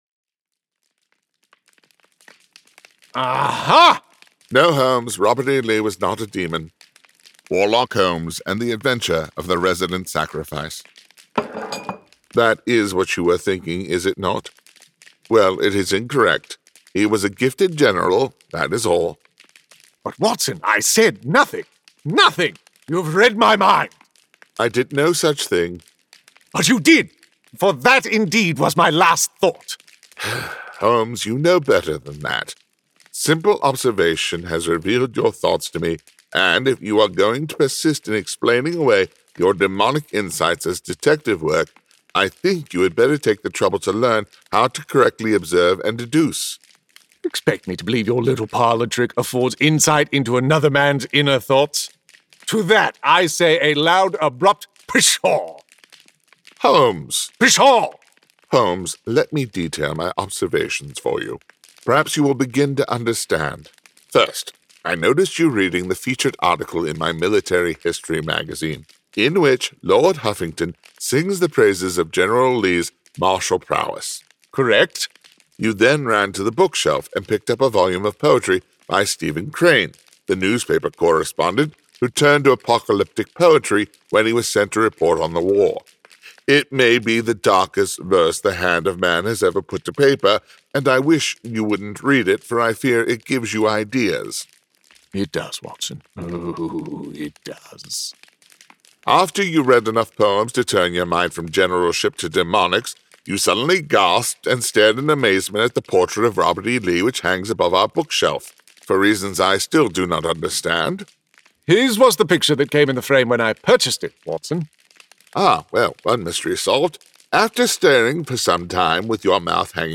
Warlock Holmes 1: A Study in Brimstone [Dramatized Adaptation]